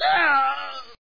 男人被打惨叫音效免费音频素材下载